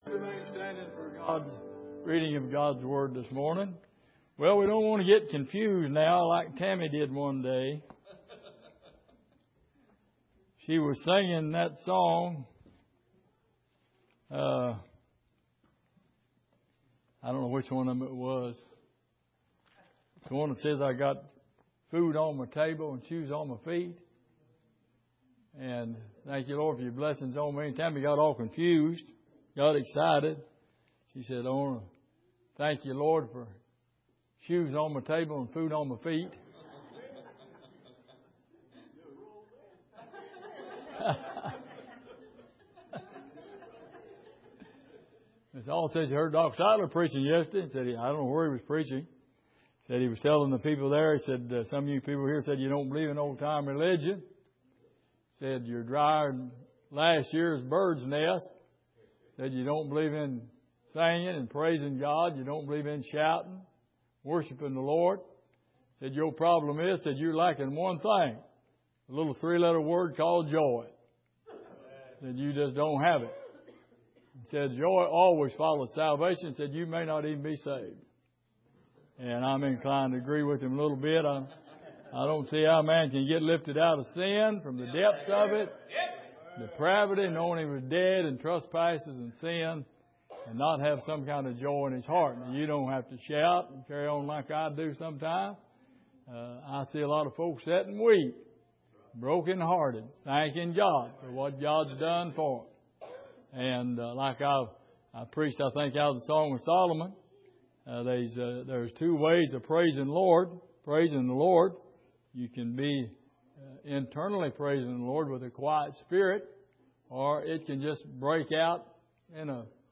Miscellaneous Passage: Luke 1:5-25 Service: Sunday Morning John The Baptist and His Parentage « Have You Got A Well?